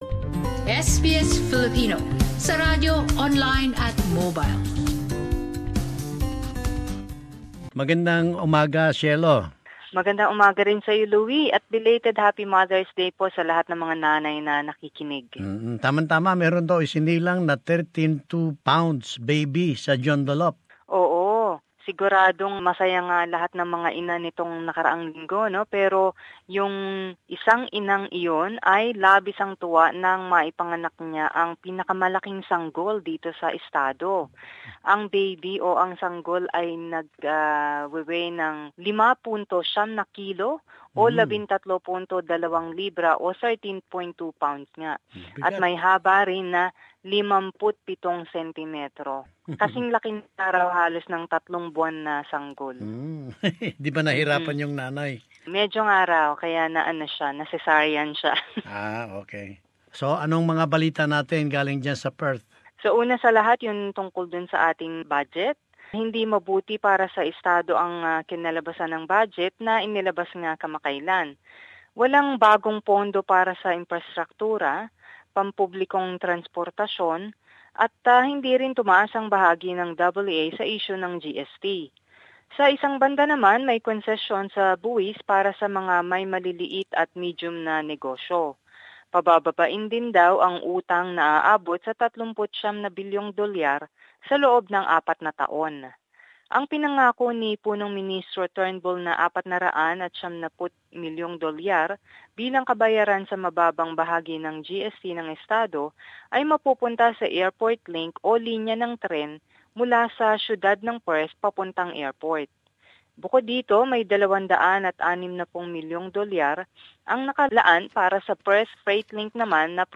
Perth Report. Summary of latest news from WA